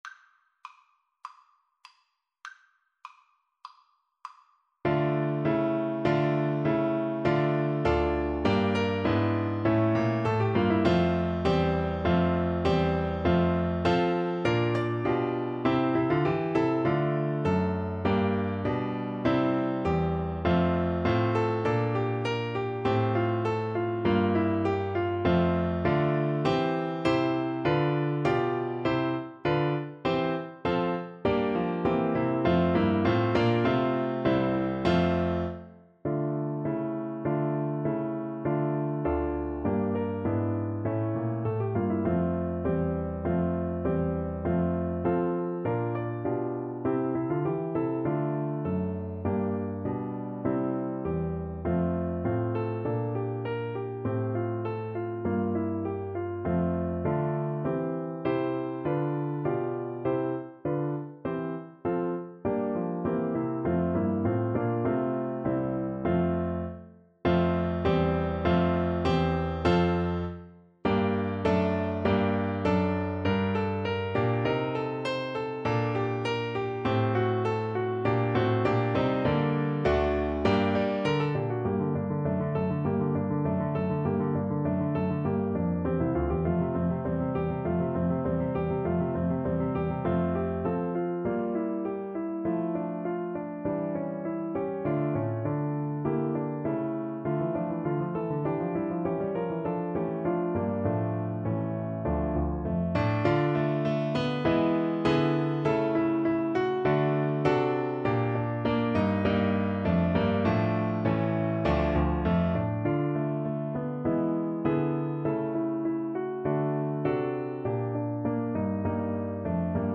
Play (or use space bar on your keyboard) Pause Music Playalong - Piano Accompaniment Playalong Band Accompaniment not yet available reset tempo print settings full screen
D minor (Sounding Pitch) E minor (Trumpet in Bb) (View more D minor Music for Trumpet )
II: Allegro (View more music marked Allegro)
Classical (View more Classical Trumpet Music)